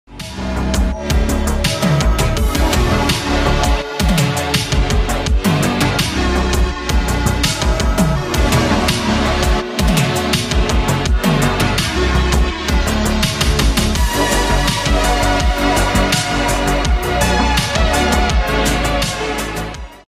Retro synthwave that sounds like a digital dream.